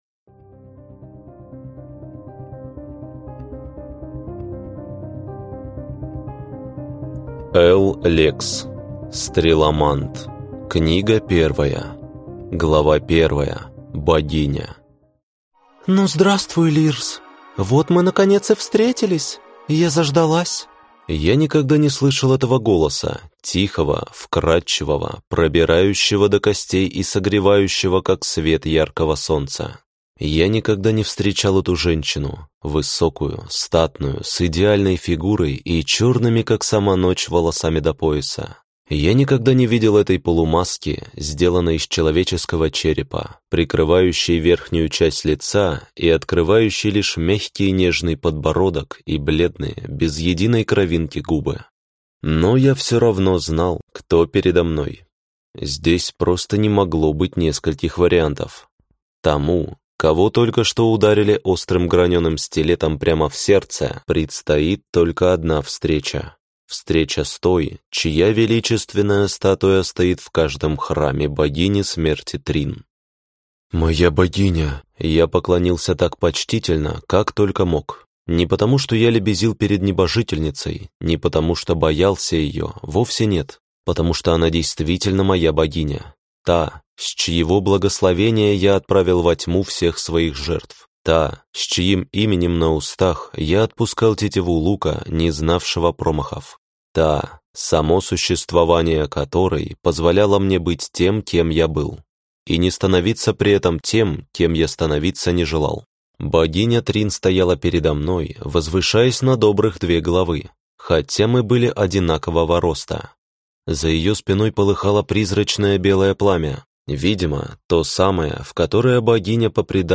Аудиокнига Стреломант. Книга 1 | Библиотека аудиокниг